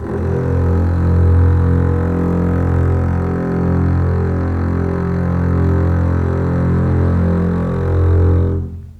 F1 LEG MF  L.wav